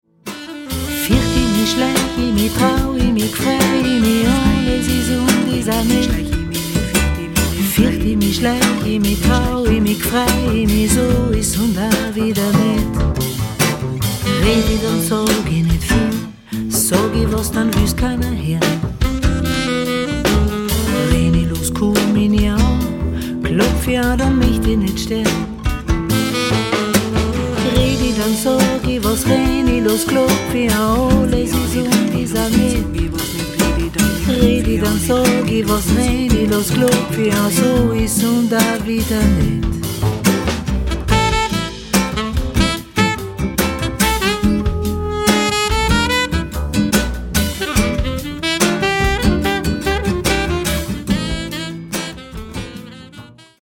Gitarre, Bass, Ukulele, Gesang
Akkordeon
Klavier, Saxophone, Flöten